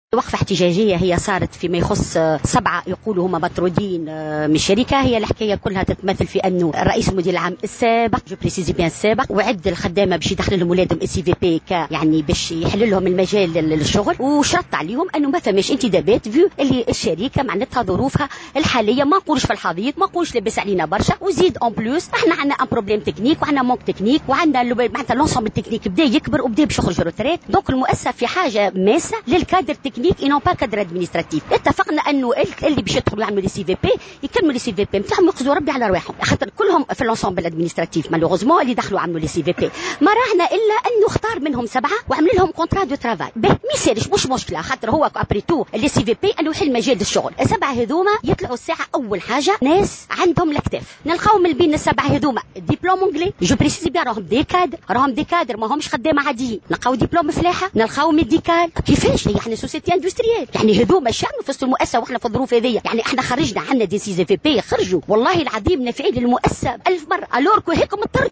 في تصريح لمراسل "الجوهرة أف أم" بالجهة